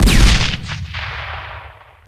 smallcannon.ogg